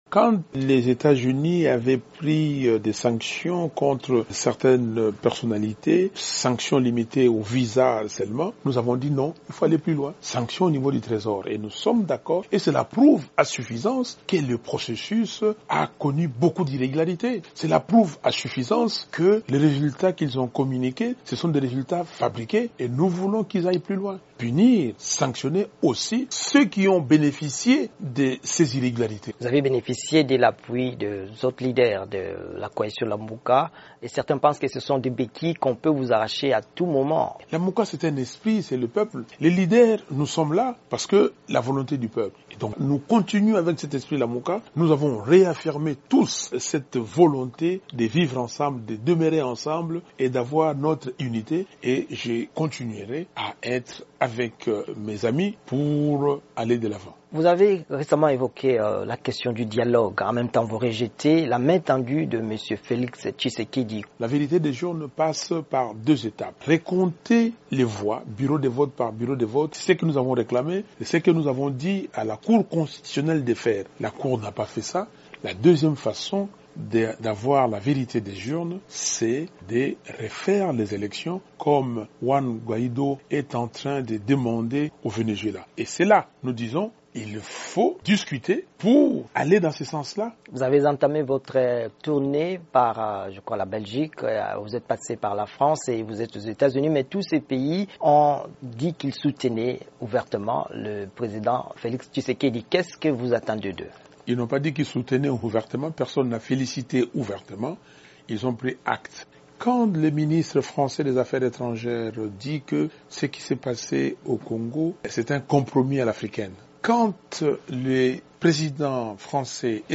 Entretien avec Martin Fayulu